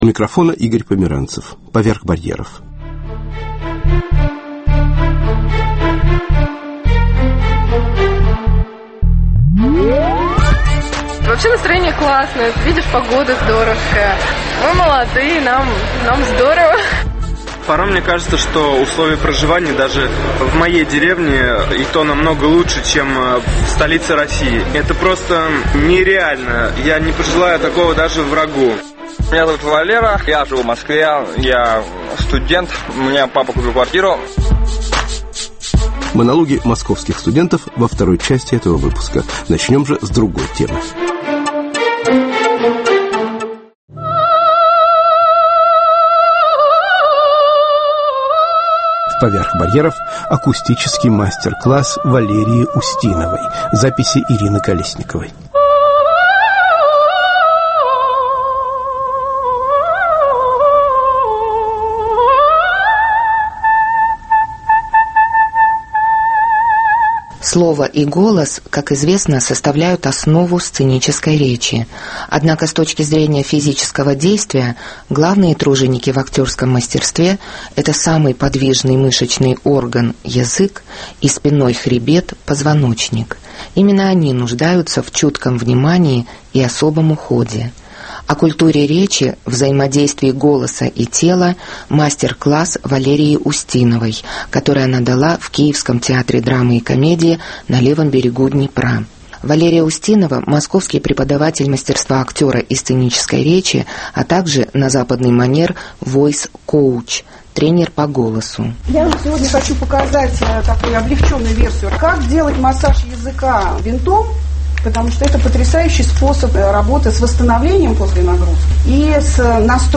Акустический мастер-класс в Киевском театр Драмы и Комедии на Левом берегу Днепра